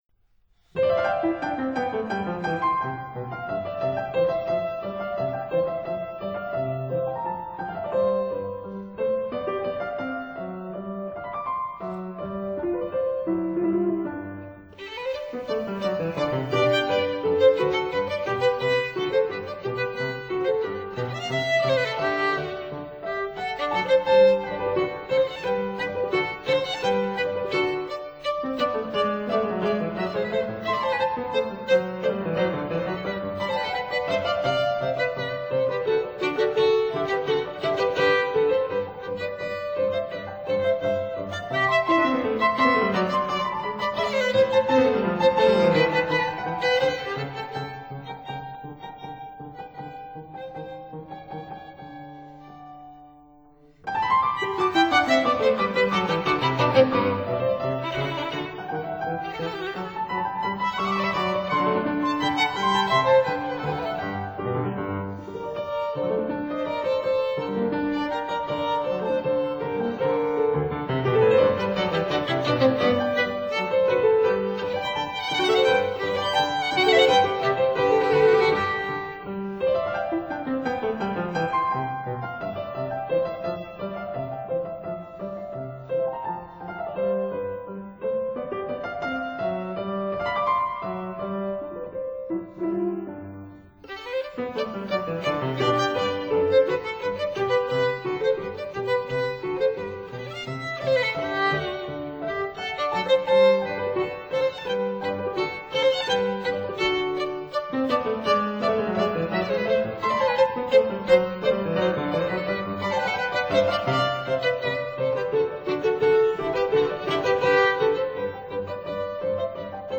類型： 古典
violin
piano